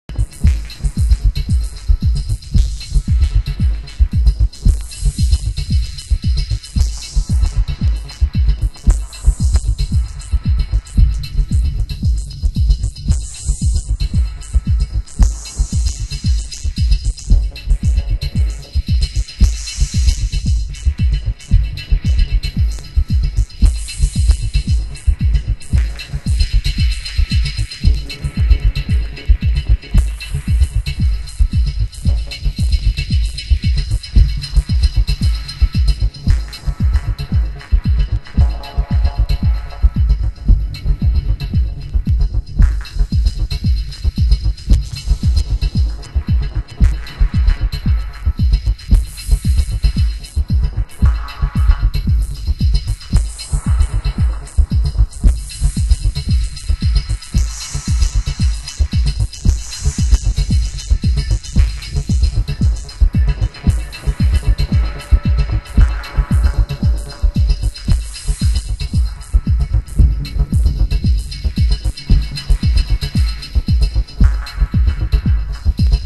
盤質：軽いスレ傷、少しチリノイズ有